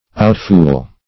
Outfool \Out*fool"\, v. t.